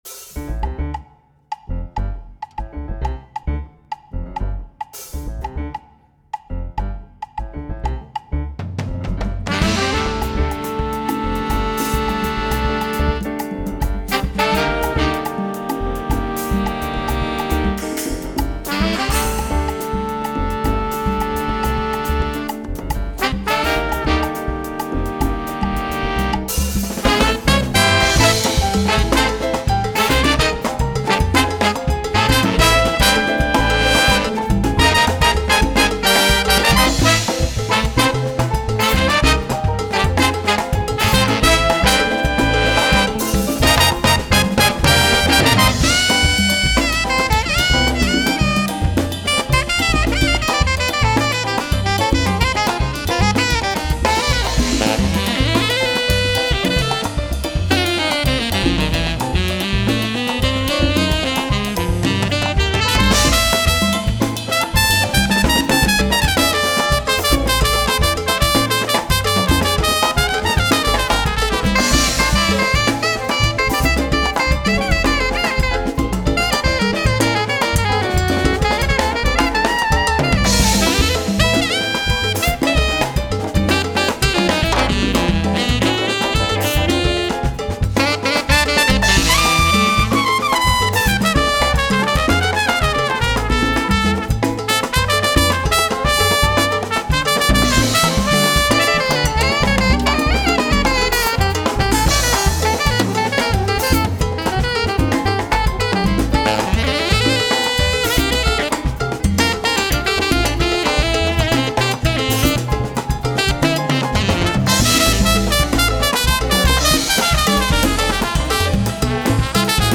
trumpet & backing vocals
trombone & backing vocals
alto sax & flute
tenor sax
bari sax
piano
baby bass
drums
percussion